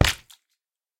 Minecraft Version Minecraft Version snapshot Latest Release | Latest Snapshot snapshot / assets / minecraft / sounds / mob / guardian / land_hit4.ogg Compare With Compare With Latest Release | Latest Snapshot
land_hit4.ogg